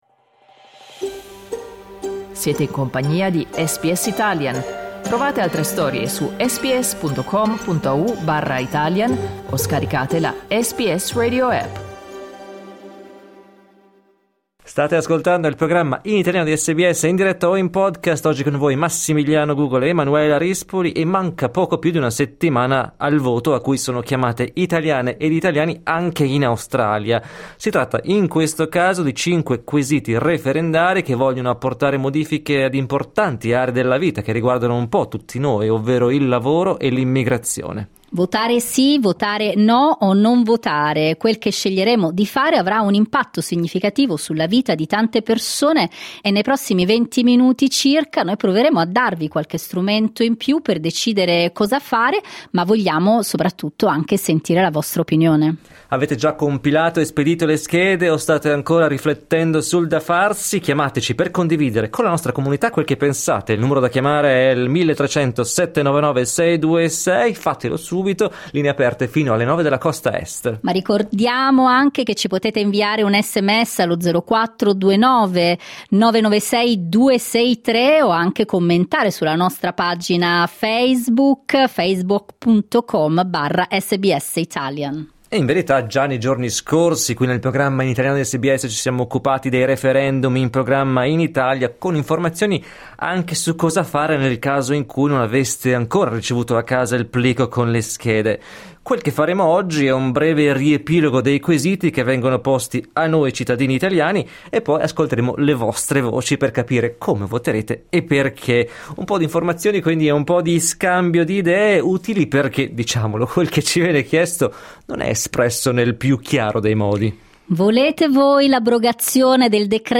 Al dibattito aperto hanno partecipato ascoltatori ed ascoltatrici di SBS Italian che ci hanno raccontato i loro dubbi ed il modo in cui li hanno risolti per poi scegliere cosa fare in modo consapevole.